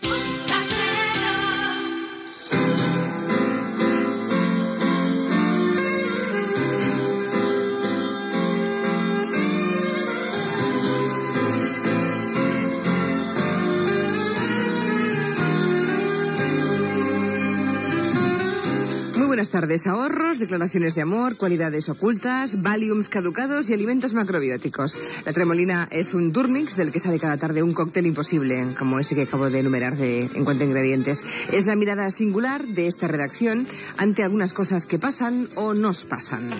Indicatiu emissora i començament del programa amb "La tremolina", la taula de redacció del programa.
Entreteniment
FM